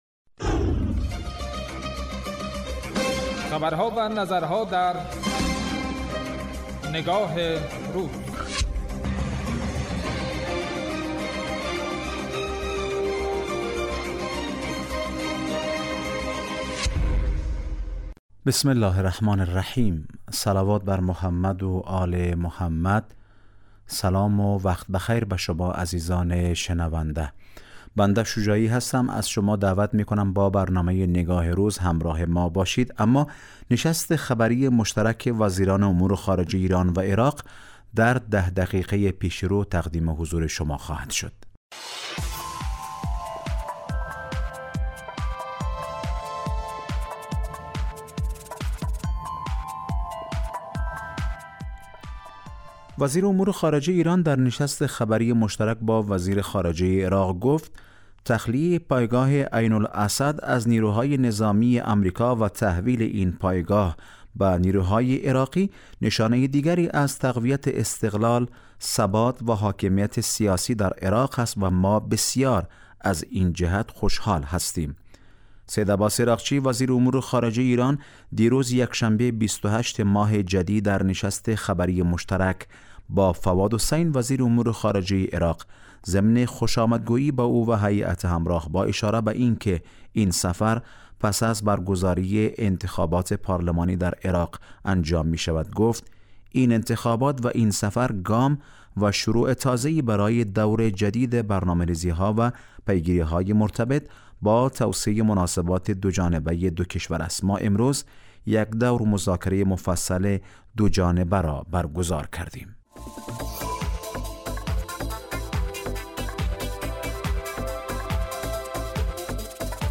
برنامه تحلیلی نگاه روز